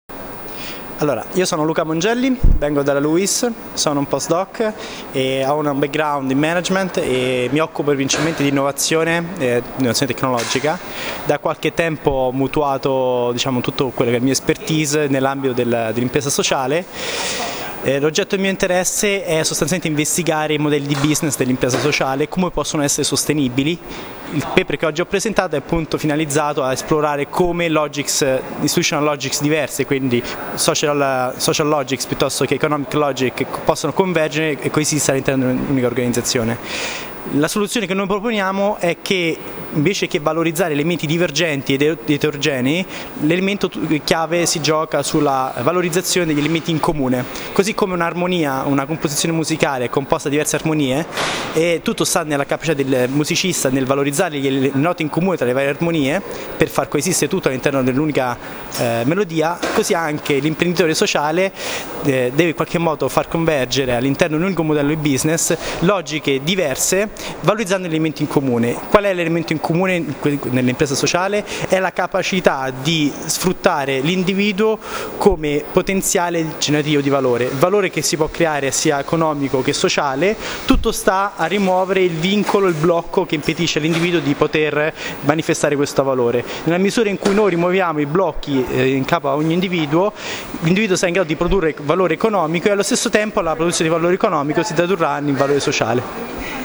Il Colloquio Scientifico sull’impresa sociale, edizione VII, si è chiuso la scorsa settimana a Torino.
A ricordo di alcuni dei loro interventi, delle brevi audio interviste mordi e fuggi sui loro temi di ricerca.